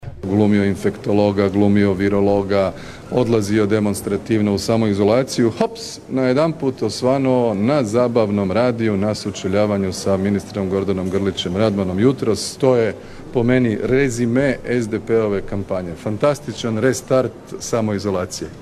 Izjava Andreja Plenkovića iz Dnevnika HRT-a.